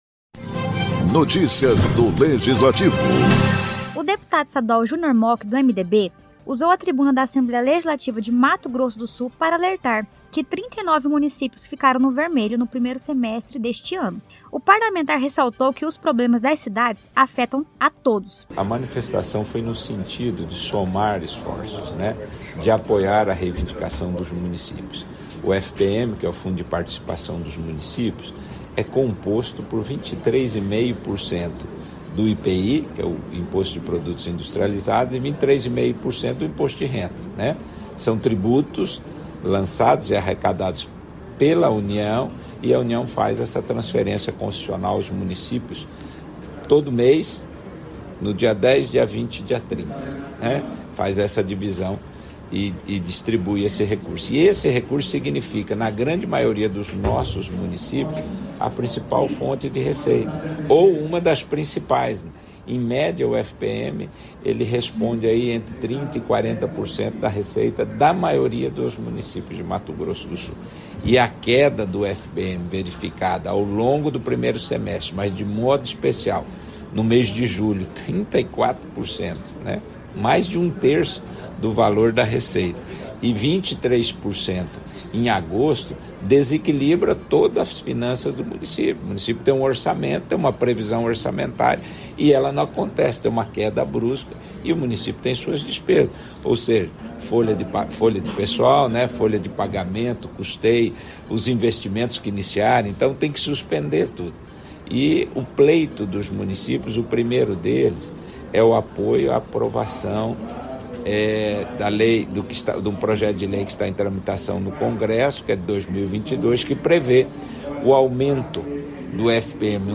O deputado estadual Júnior Mochi (MDB), usou a tribuna da Assembléia Legislativa de Mato Grosso do Sul, para alertar que 39 municípios ficaram no vermelhos no primeiro semestre deste ano.